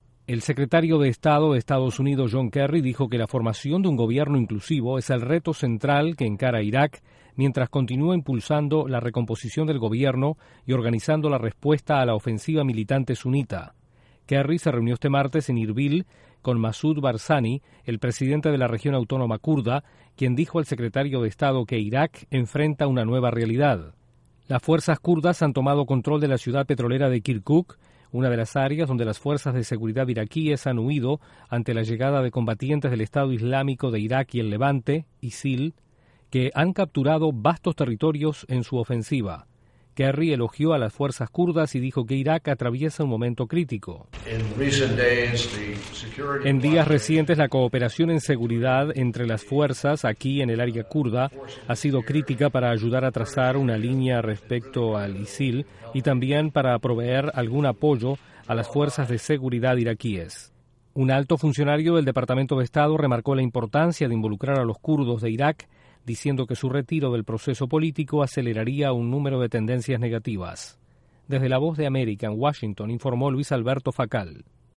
El secretario de Estado norteamericano, John Kerry, se reúne con líderes kurdos en busca de reformas políticas en Irak. Desde la Voz de América en Washington